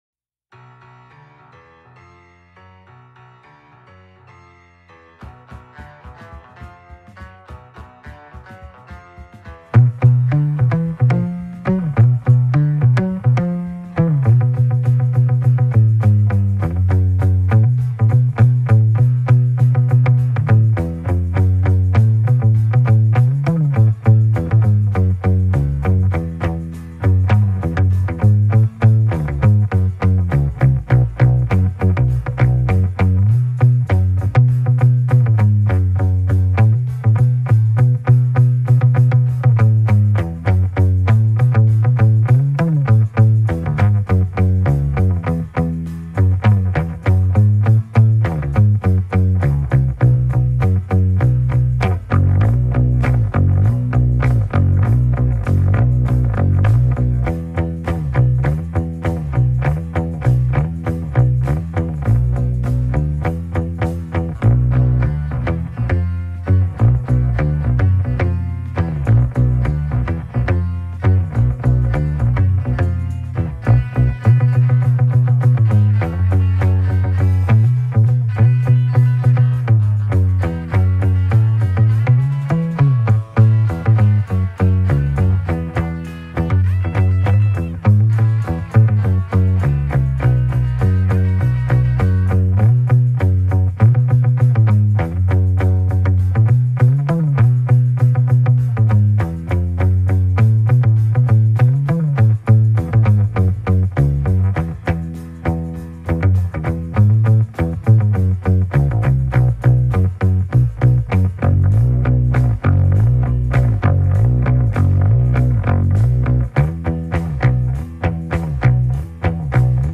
98% flatwound Rickenbacker snap and 2% forgivable slop